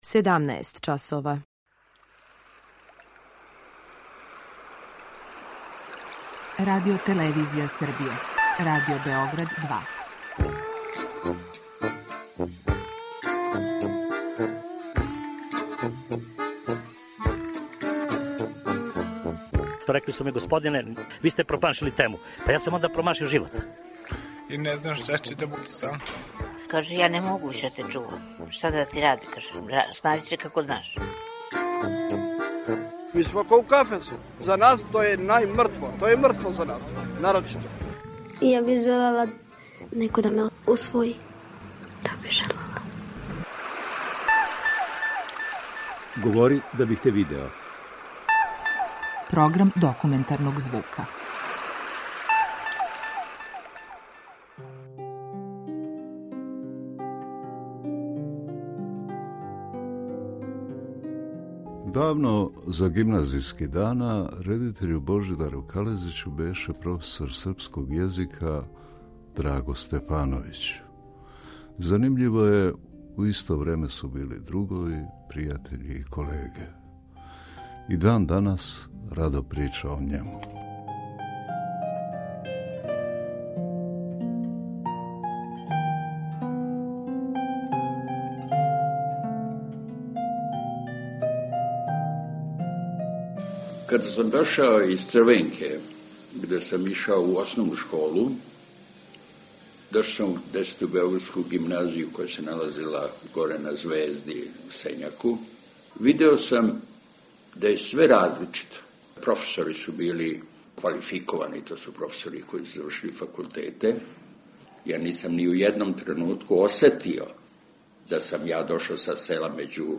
Документарни програм
Серија полусатних документарних репортажа